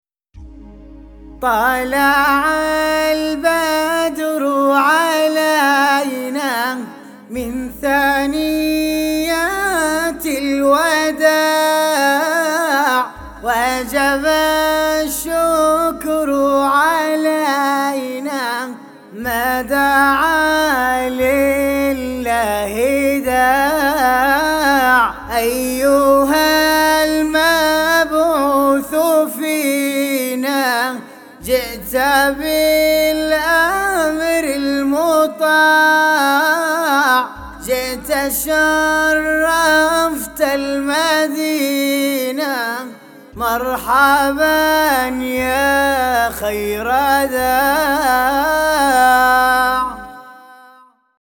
نهاوند